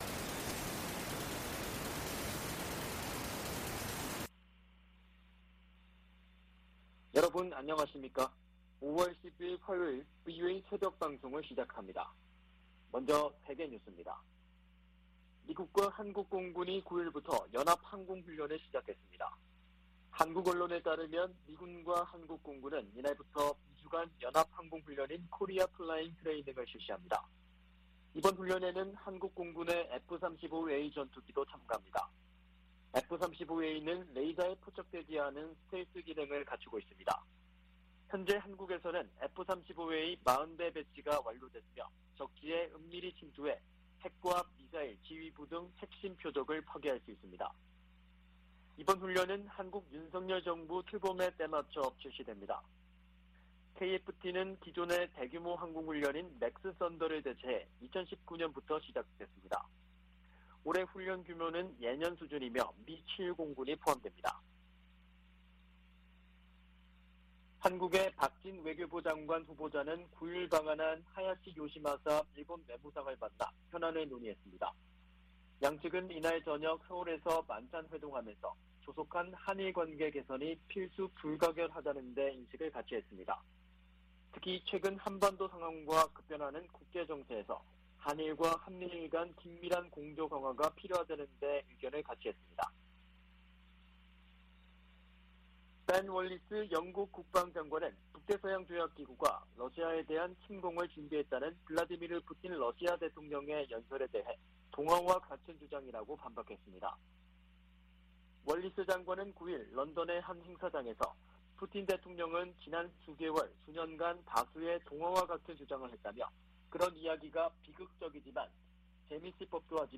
VOA 한국어 '출발 뉴스 쇼', 2022년 5월 10일 방송입니다. 북한이 7일 오후 함경남도 신포 해상에서 잠수함발사 탄도미사일(SLBM)을 발사했습니다. 미 국무부는 미사일 도발을 이어가는 북한을 규탄하고 한・일 양국에 대한 방어 공약을 재확인했습니다. 미 하원에서 오는 12일 올해 첫 대북 정책 청문회가 개최될 예정입니다.